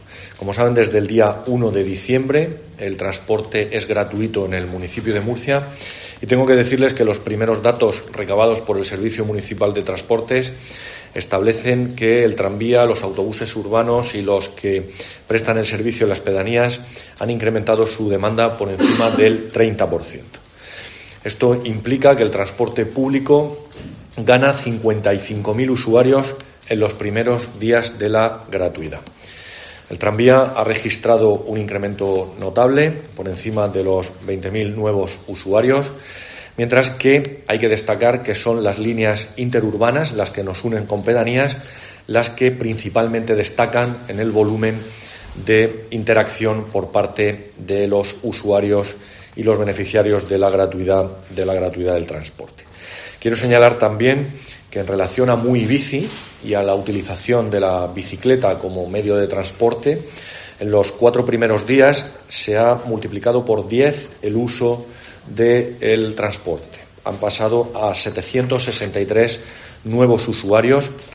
José Francisco Muñoz, concejal de Movilidad, Gestión Económica y Contratación